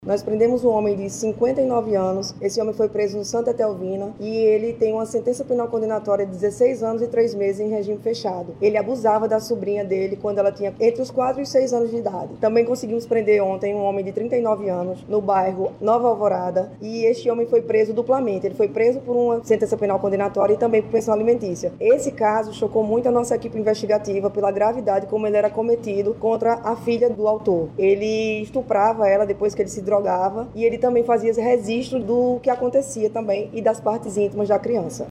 A delegada detalha, ainda, os casos dos outros dois homens presos por abusarem de suas sobrinha e filha.